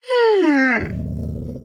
Minecraft Version Minecraft Version 1.21.5 Latest Release | Latest Snapshot 1.21.5 / assets / minecraft / sounds / mob / wolf / angry / whine.ogg Compare With Compare With Latest Release | Latest Snapshot
whine.ogg